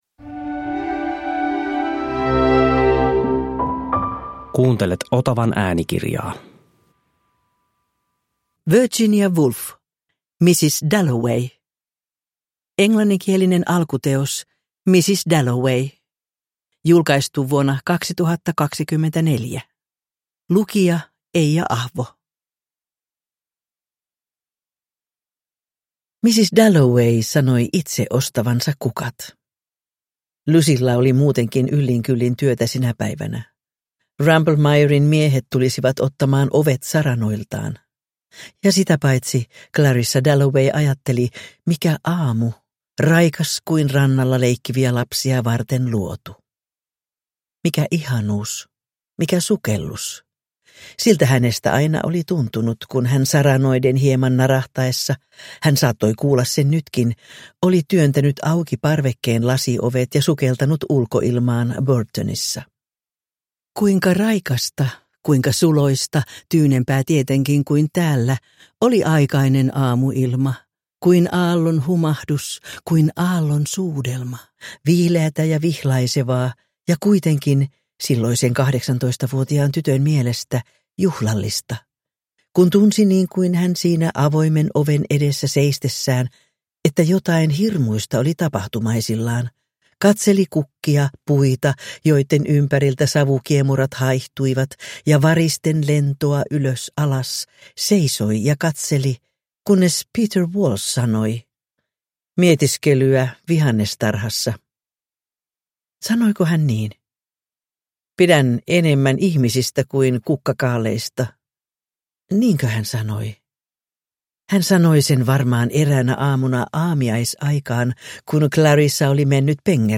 Mrs. Dalloway – Ljudbok